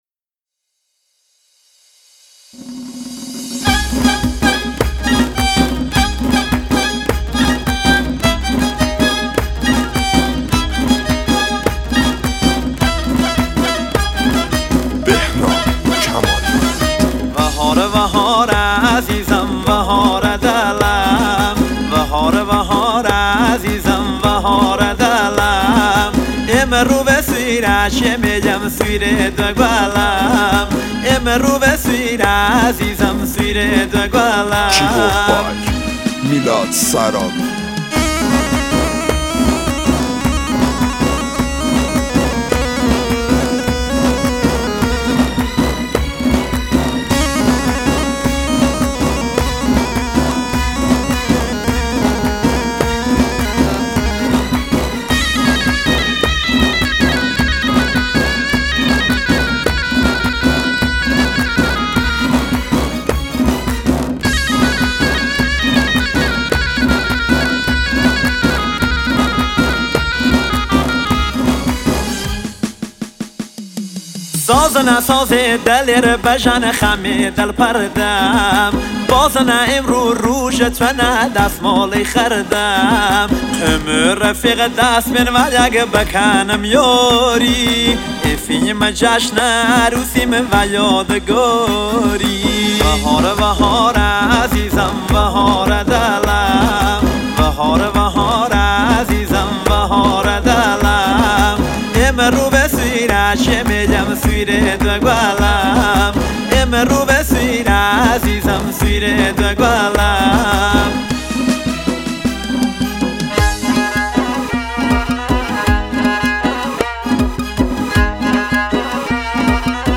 آهنگ لری